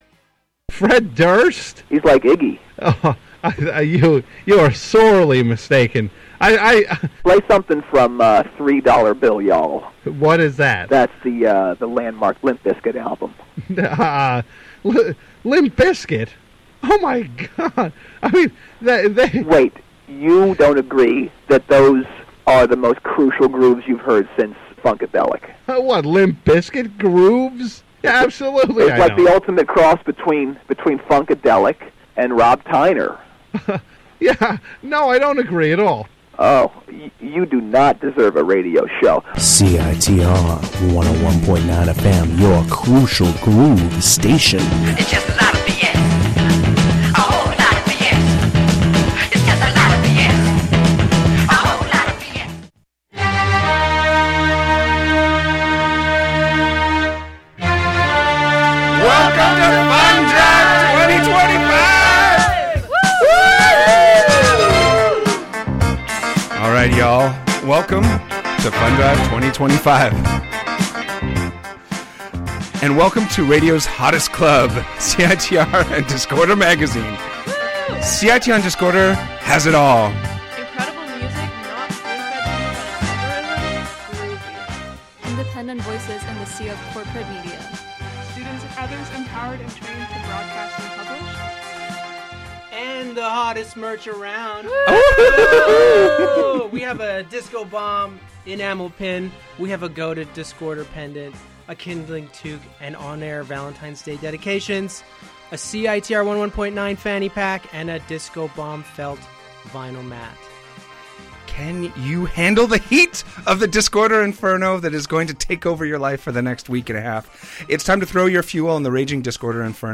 We kicked off fundrive with a DISCO INFERNO.